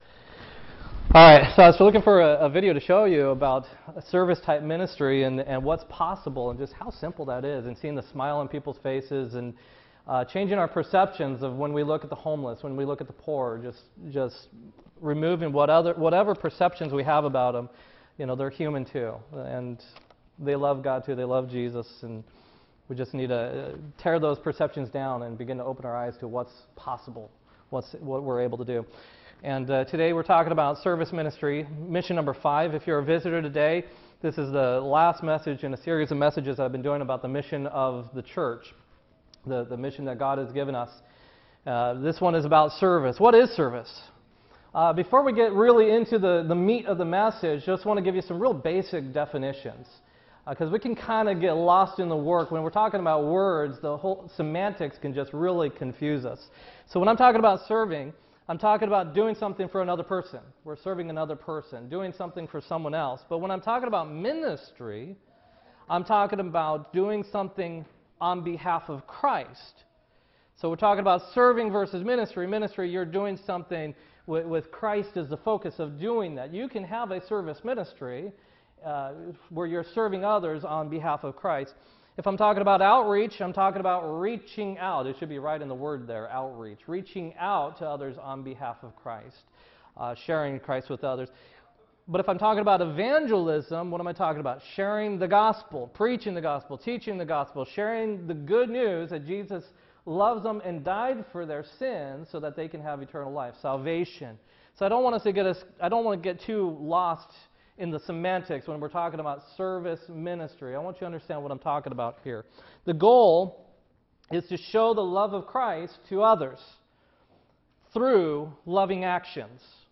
9-3-2016-sermon